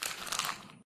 paper_scrunch_1_quieter.ogg